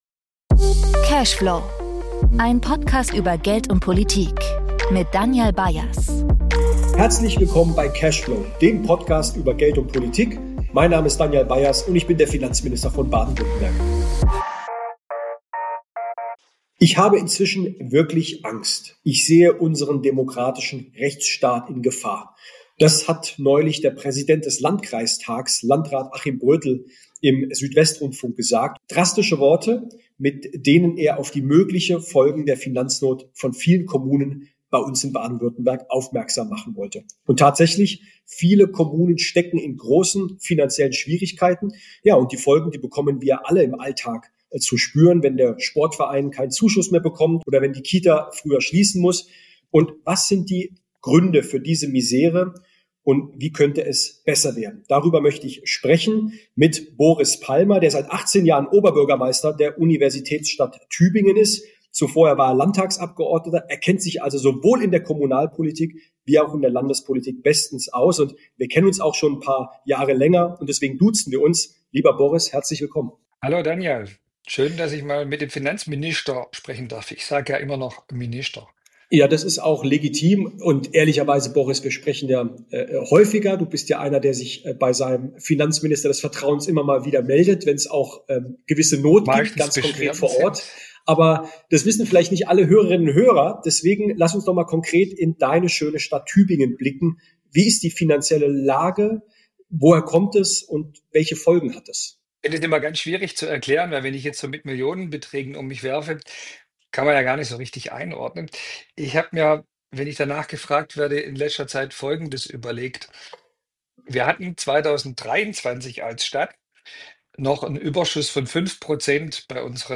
Viele Städte und Gemeinden kämpfen mit steigenden Ausgaben, sinkenden Einnahmen und wachsender Unsicherheit. Finanzminister Danyal Bayaz spricht mit dem Tübinger Oberbürgermeister Boris Palmer über die finanzielle Not der Kommunen und die gravierenden Folgen für Bürgerinnen und Bürger.